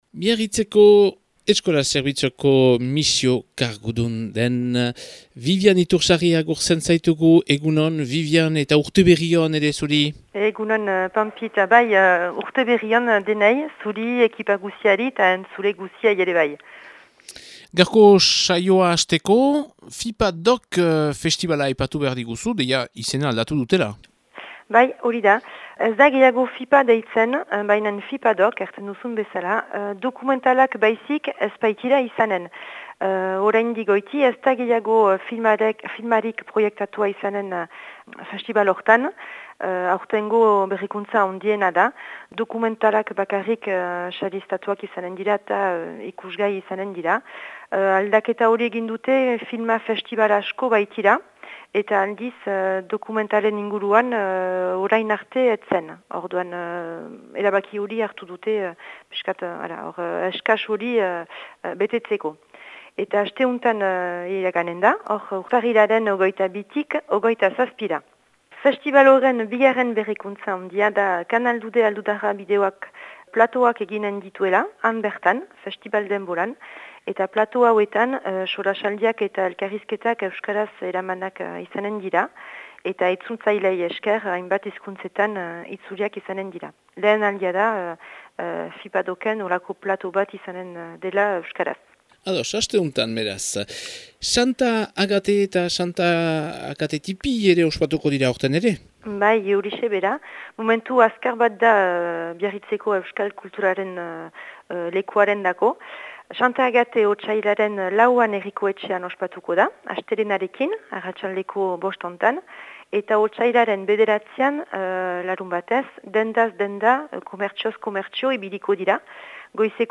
Urtarrilaren 24ko Biarritzeko berriak